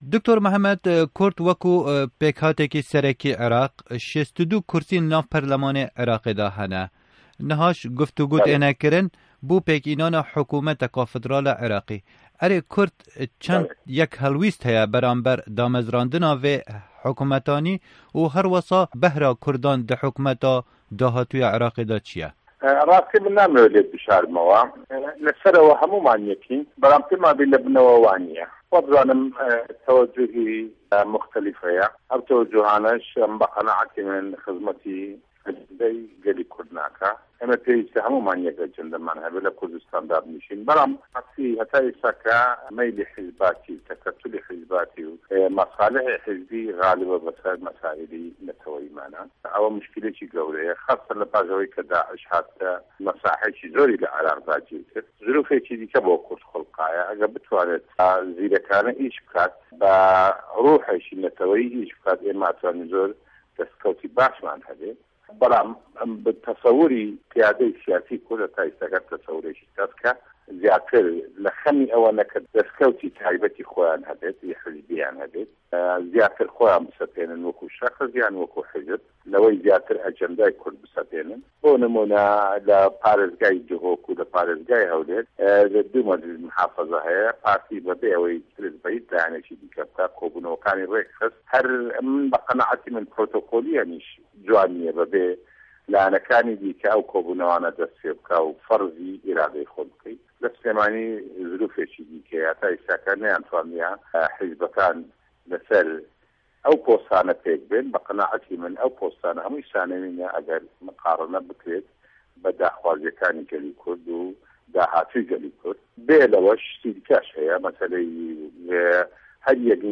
Di hevpeyvînekê de ligel Dengê Amerîka endamê berê yê parlamanê Îraqê Dr. Mihemed Kiyanî dibêje, berjewendiyên hizbayetî li pêş berjewendîyên welat û gel tên.
Hevpeyvîn bi Dr. Mihemed Kiyanî re